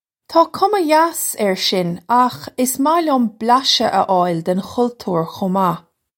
Pronunciation for how to say
Taw kumma yass air shin akh iss mah lyum blasha uh oil den khul-toor kho mah.
This is an approximate phonetic pronunciation of the phrase.